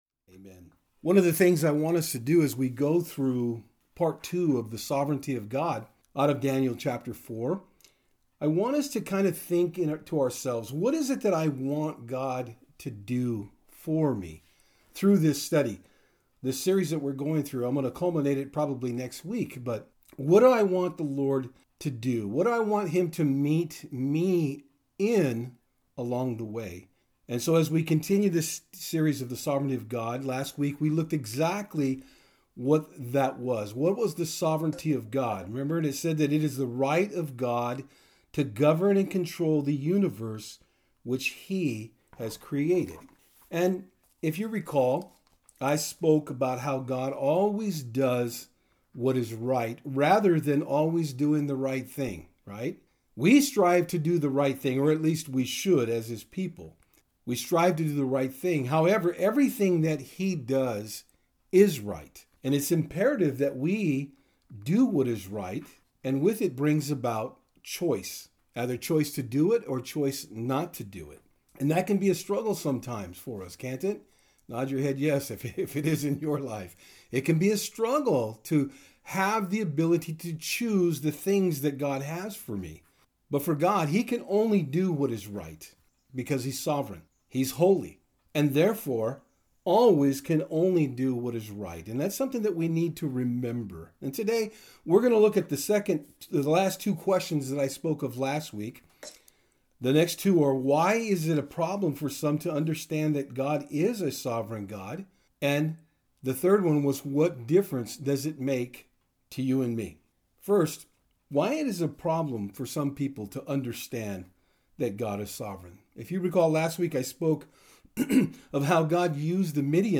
Service Type: Sundays @ Fort Hill